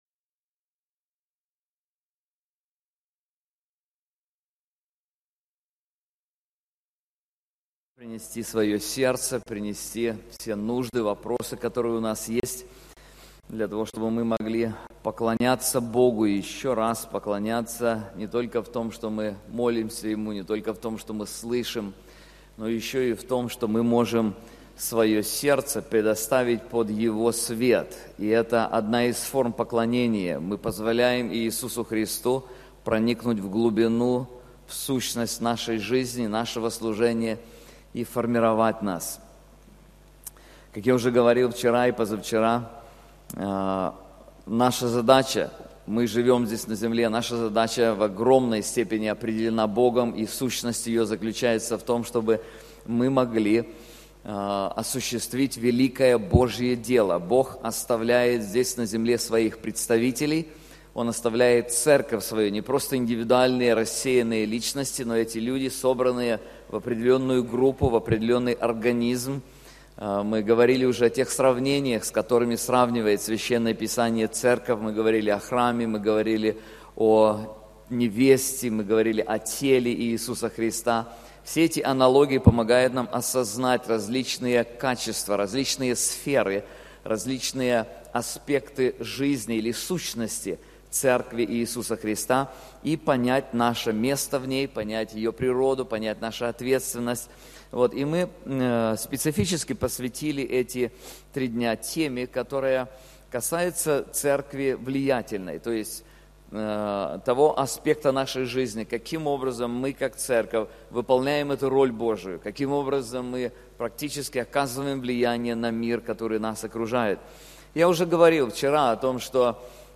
Конференции Церковь, изменяющая мир